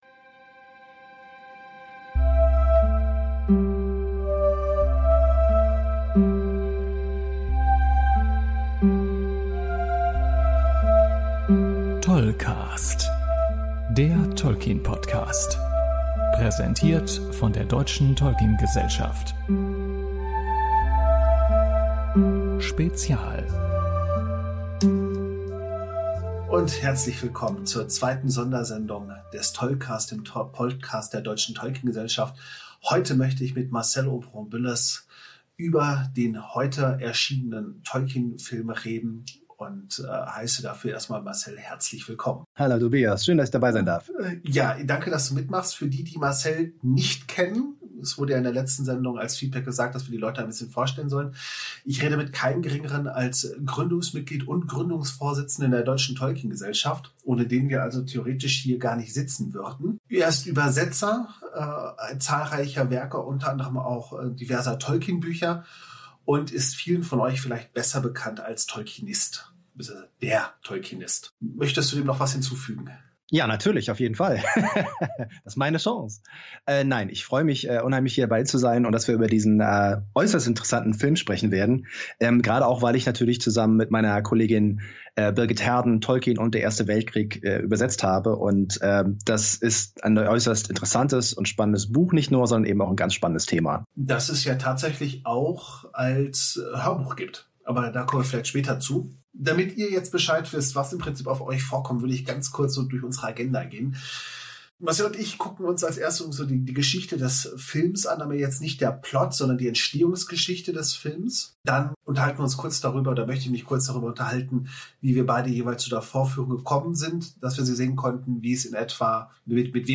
Deshalb klingt er ein wenig blechern, es geht ihm aber gut.